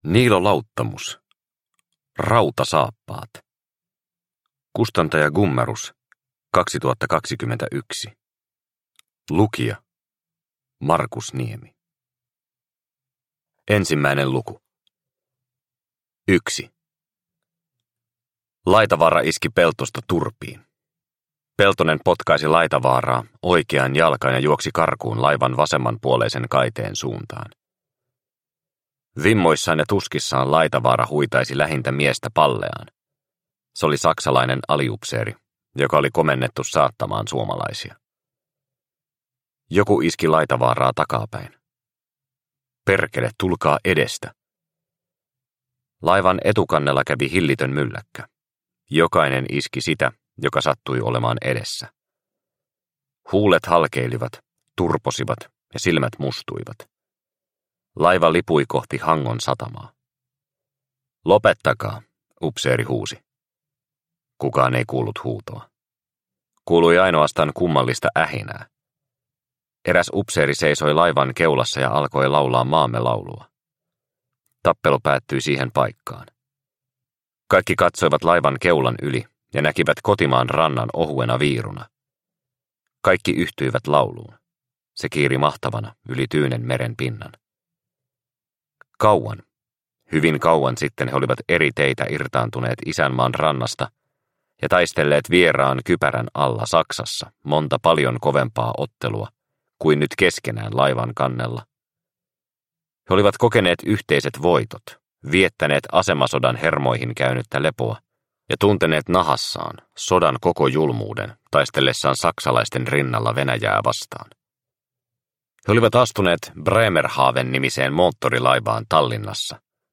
Rautasaappaat – Ljudbok – Laddas ner